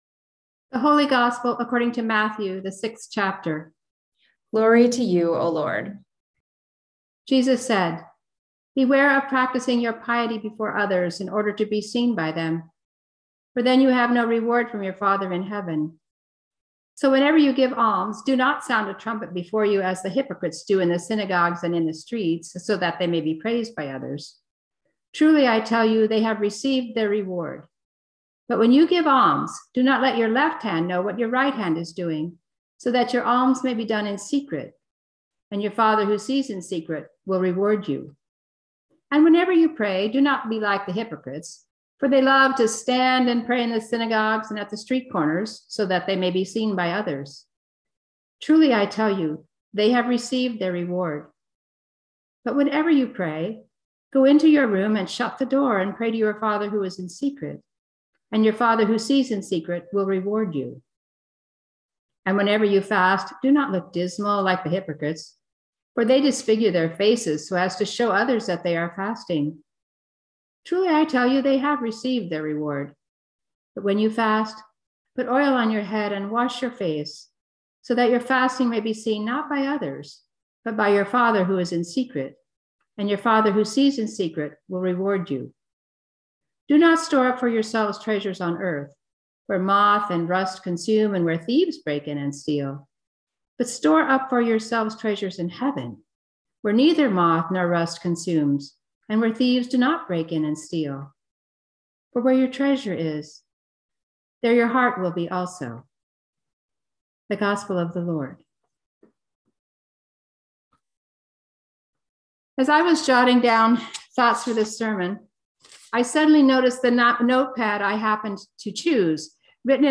Just Remember: Sermon for Ash Wednesday 2022